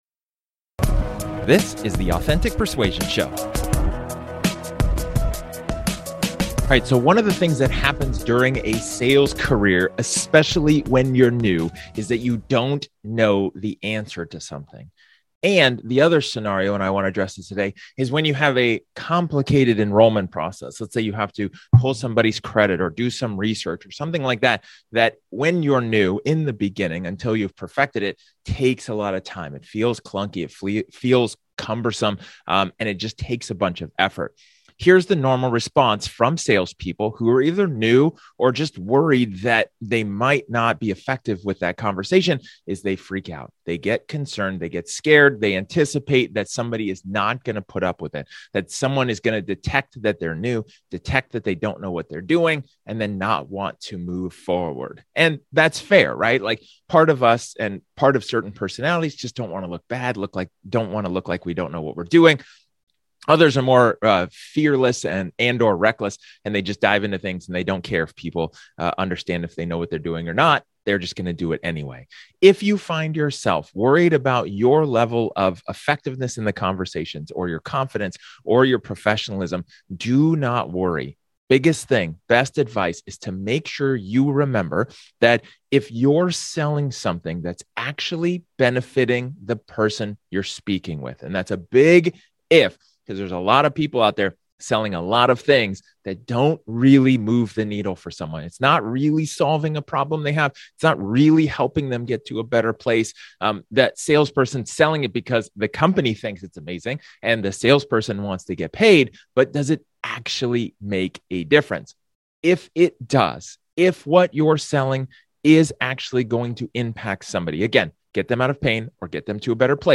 In this solo episode, I talk about having a fluent sales process even though you are just new in the world of sales.